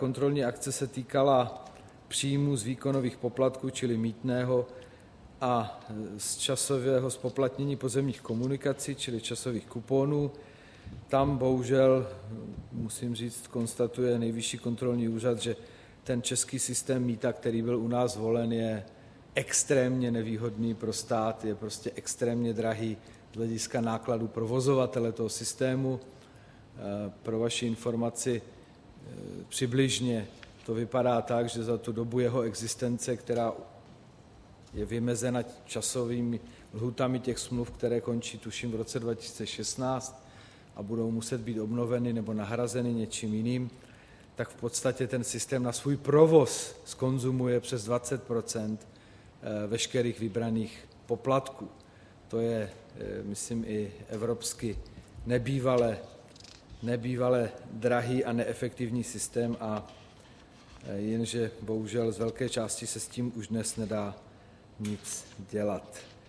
Systém na svůj provoz zkonzumuje přes 20 % veškerých vybraných poplatků. To je, myslím, i evropsky nebývale drahý a neefektivní systém, jenže bohužel z velké části se s tím už dnes nedá nic dělat,“ vyjádřil se premiér Jiří Rusnok mimo jiné ke kontrolnímu závěru na tiskové konferenci po jednání vlády.